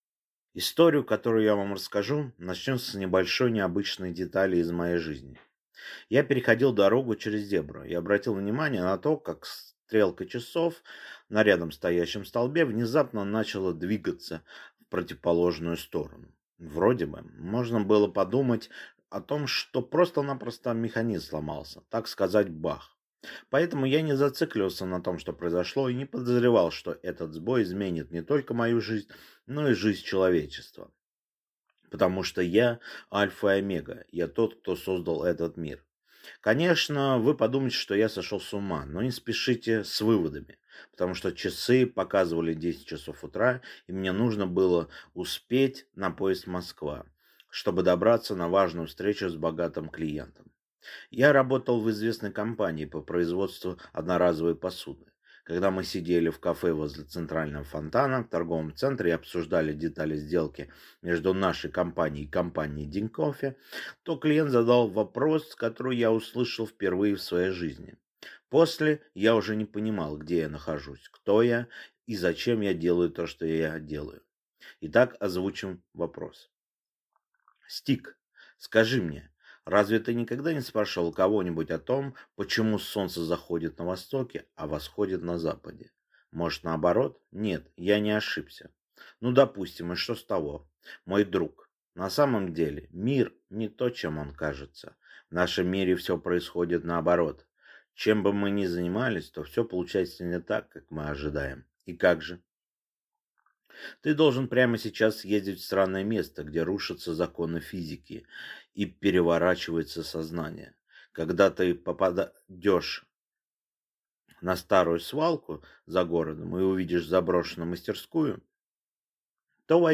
Аудиокнига Я – Альфа и Омега | Библиотека аудиокниг